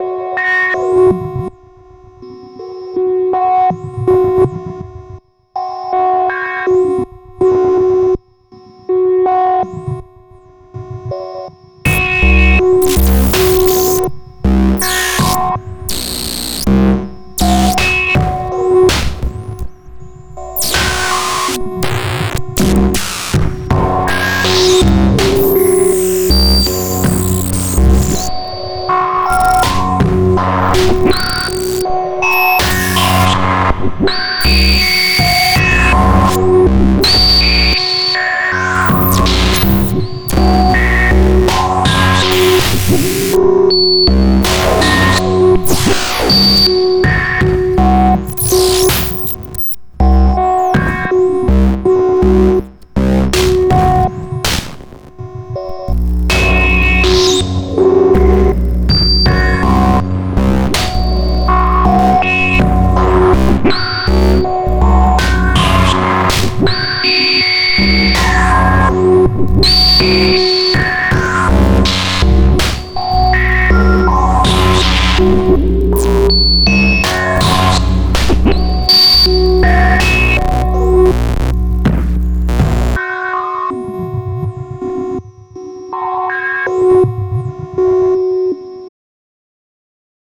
This is a couple passes with tons of internal modulation and locks doing things to the filters and the AM. Drums from the RYTM via the Landscape Stereofield in keeping with the feedback theme and a touch of Valhalla Room.
metal yet organic.